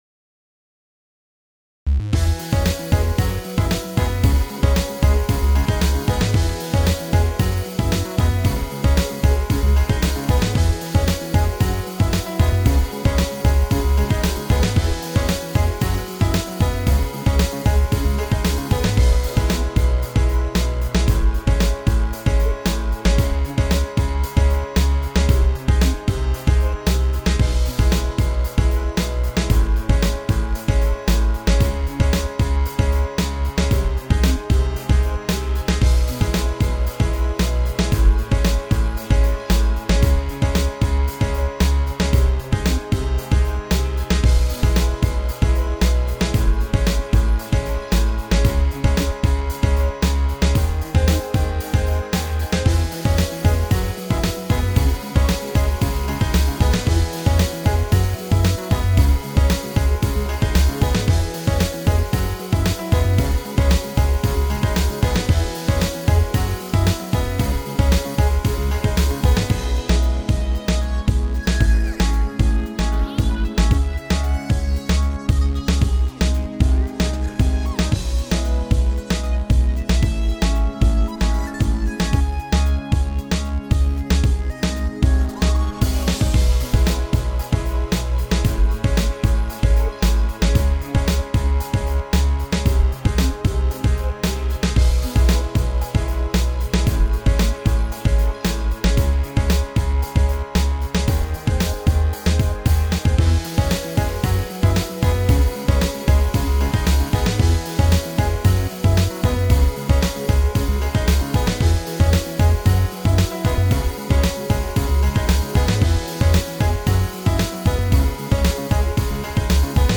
So the inevitable backlash to the fake drums that sound real is happening. this song went with a very processed snare and kick and kept "real" sounding hats and cymbals. I think it gave it more of that electronic feel I was going for.
I tried making it sound deeper with reverb, tried making it sound dirtier with distortion, and tried darkening the tone with a different piano patch, but none of them seem to work.
The synth solo at 1:10 is what I'm feeling at the moment.